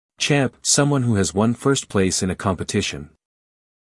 英音/ tʃæmp / 美音/ tʃæmp /